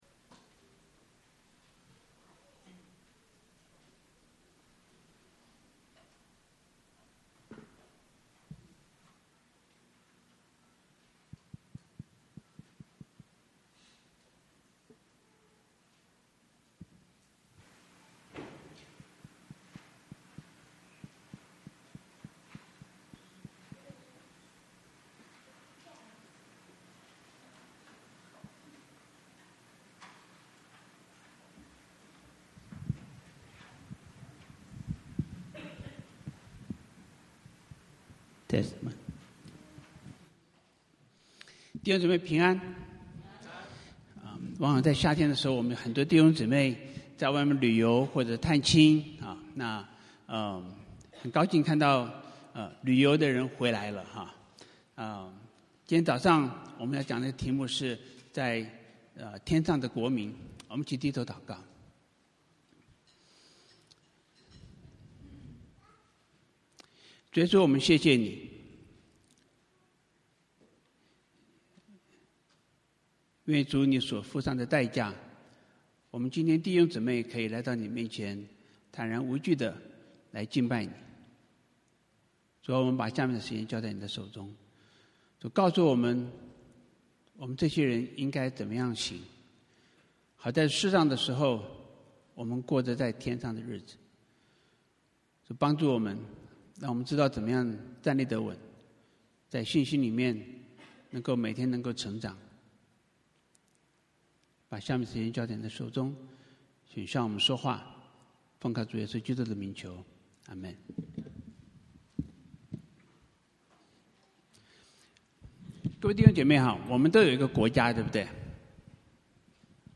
证道主题: 生命的考验